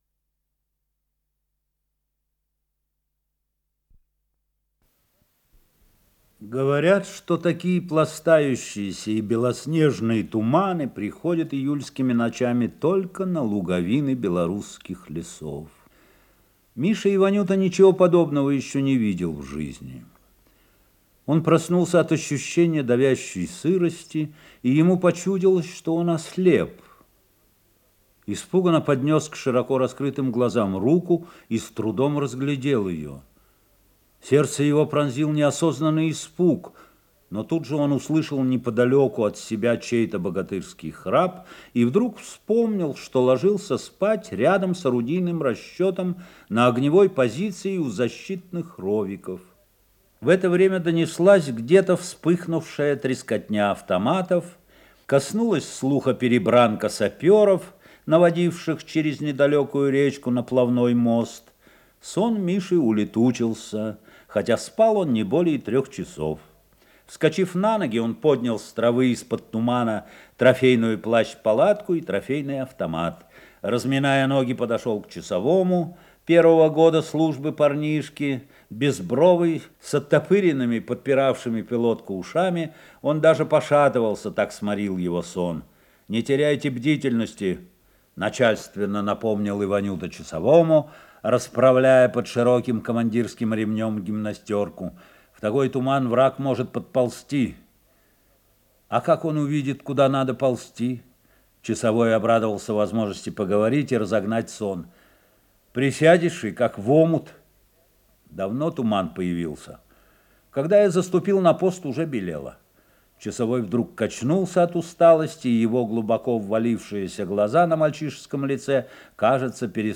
Исполнитель: Георгий Жженов - чтение